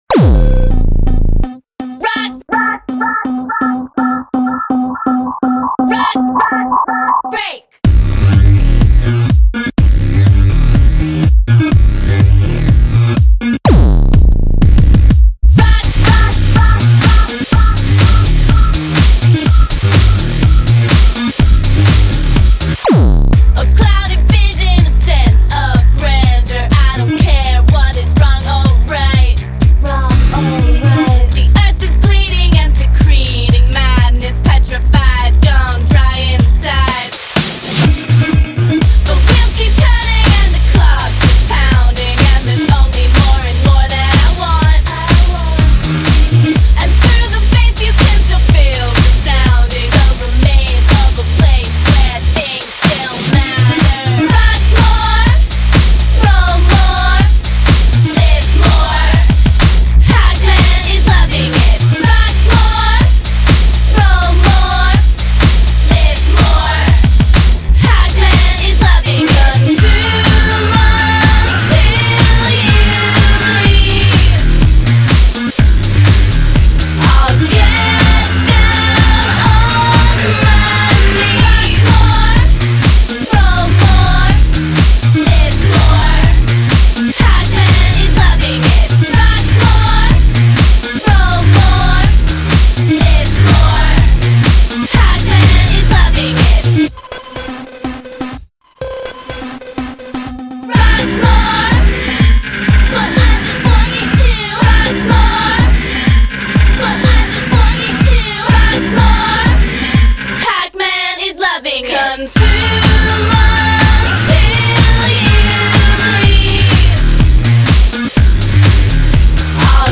BPM124
MP3 QualityMusic Cut